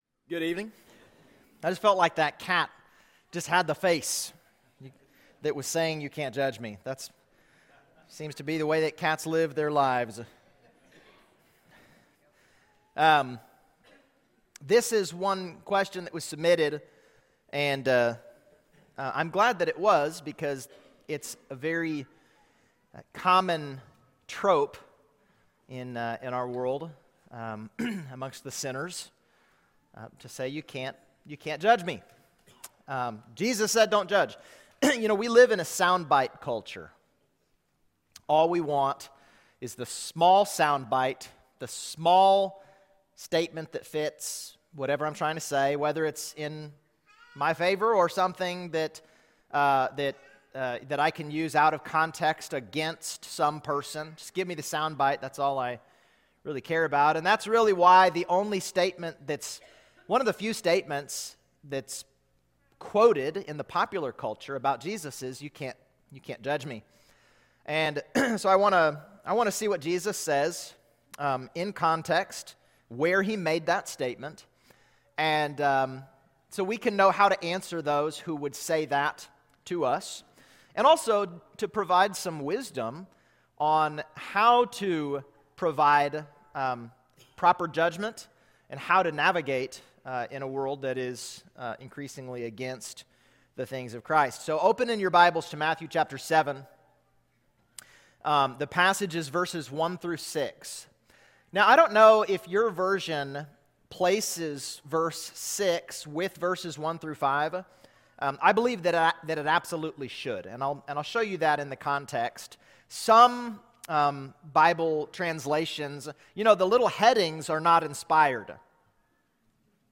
SundayPMSermon12-7-25.mp3